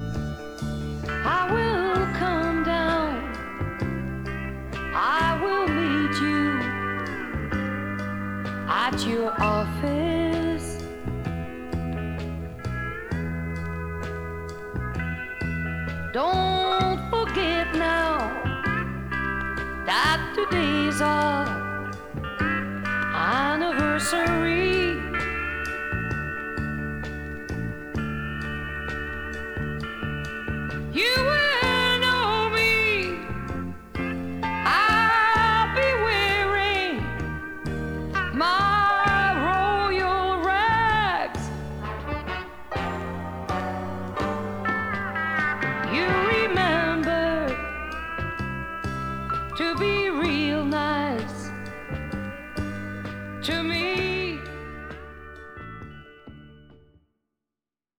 Recorded: Olympic Sound Studio in Barnes / London, England